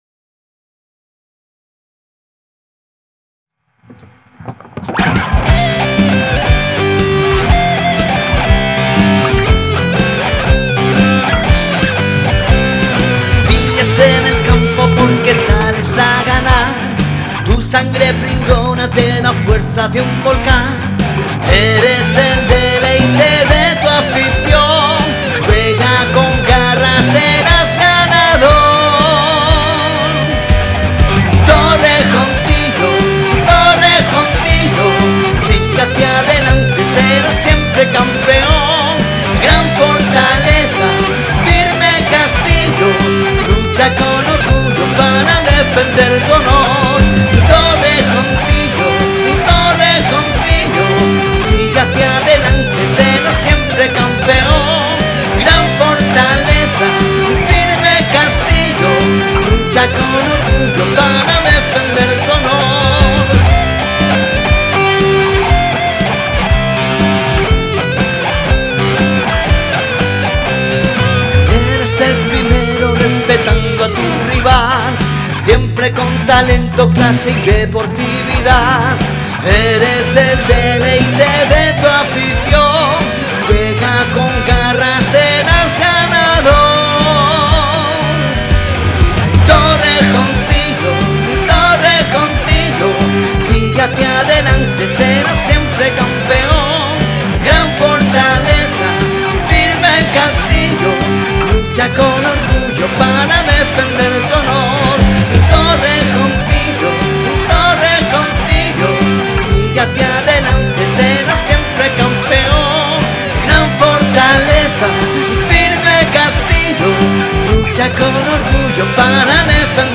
Y para despedir y animar a nuestro club, os dejamos con el himno compuesto especialmente para este equipo y que se estrena en este partido tan especial.
Himno.mp3